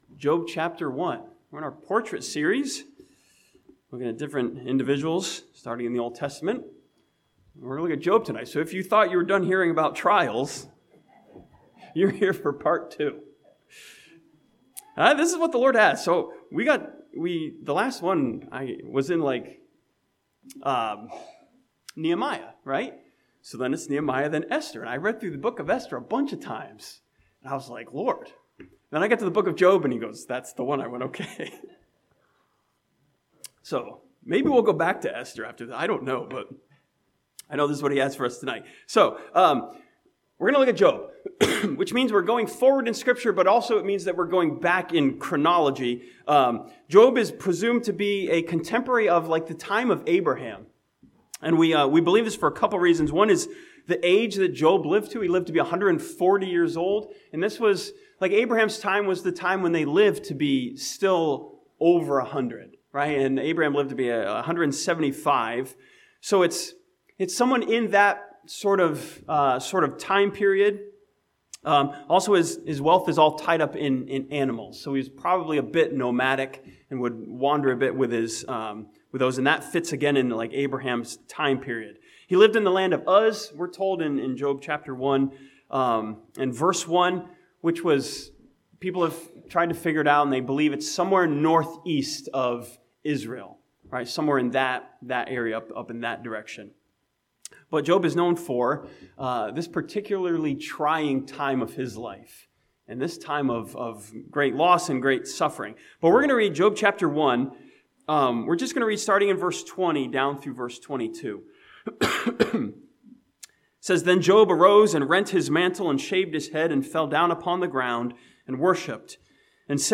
This sermon from Job chapter 1 looks at Job as a portrait of adoration as he continues to be devoted to God.